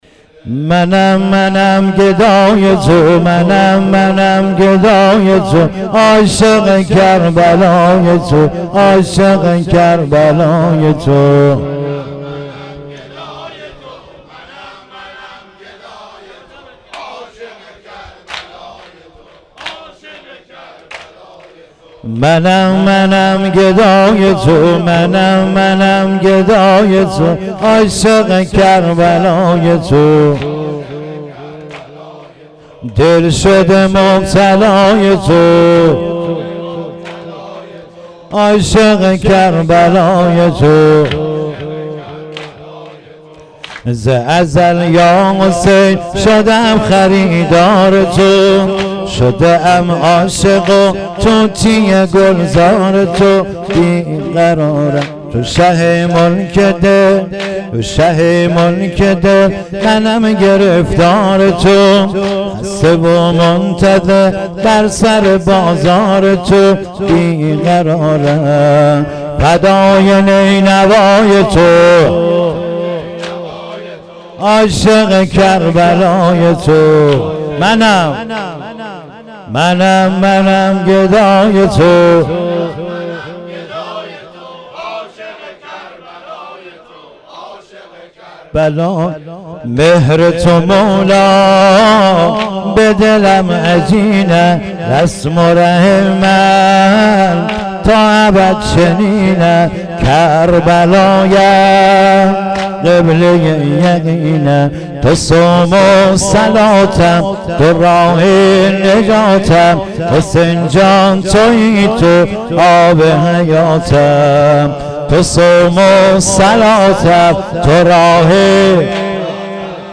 محرم و صفر 1396